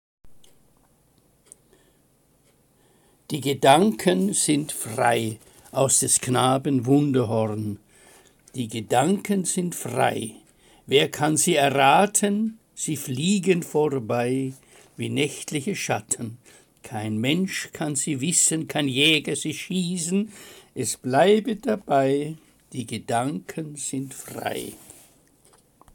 Lesung Gedichte aus der Romantik